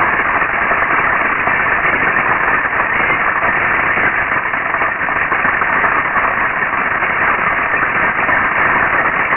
Mars Microphone: Test Sound Data (Lava Flow)
lavaflow.wav